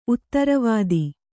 ♪ uttaravādi